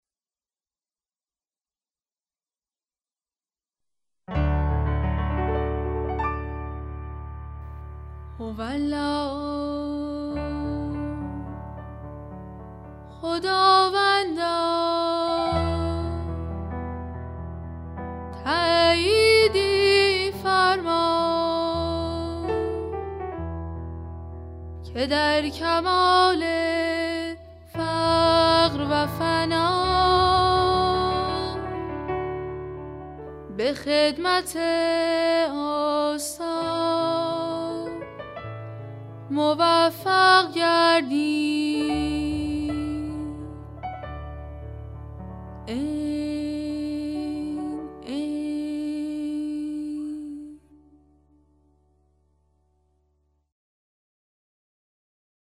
مناجات های صوتی